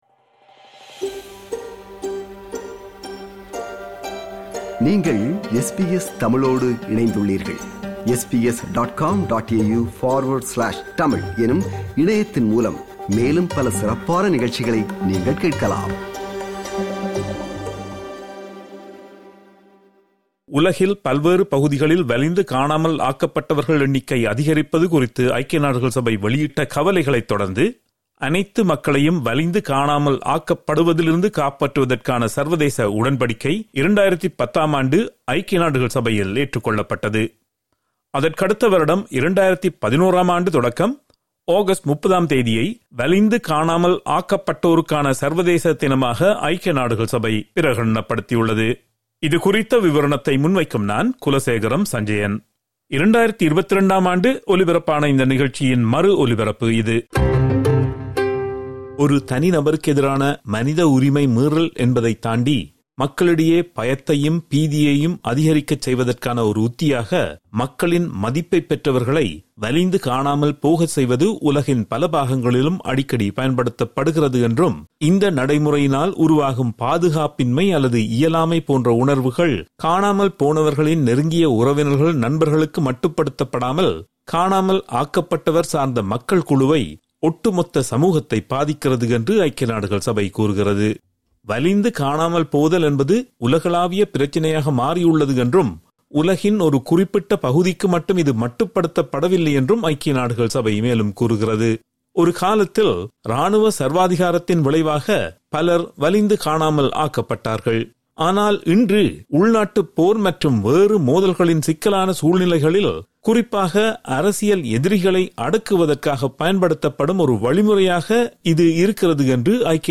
ஒரு விவரணத்தை முன்வைக்கிறார்
2024ஆம் ஆண்டு ஒலிபரப்பான நிகழ்ச்சியின் மறு ஒலிபரப்பு இது.